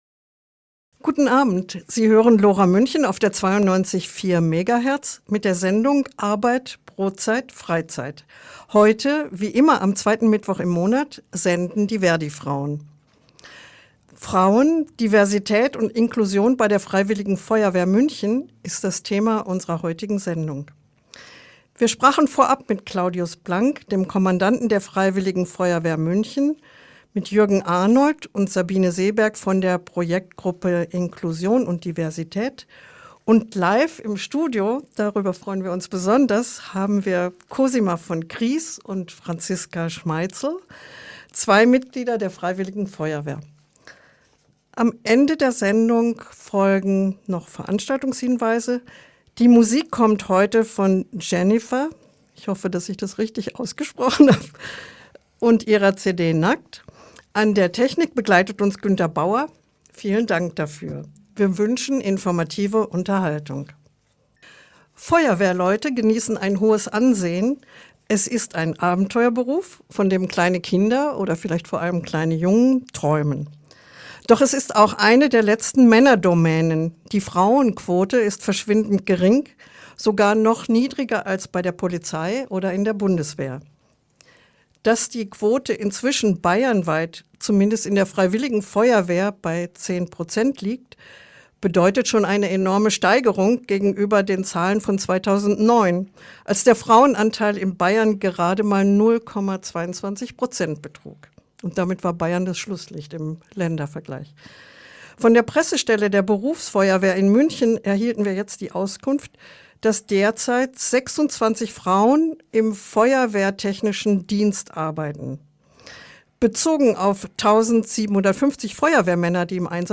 Als MP3-Datei herunterladen (45 MB) Quelle: Radio L O RA , Sendung „ Frauen, Diversität und Inklusion bei der Freiwilligen Feuerwehr München “ vom 14.08.2025. Radio L O RA ist ein Freies Radio, auch Community Media genannt.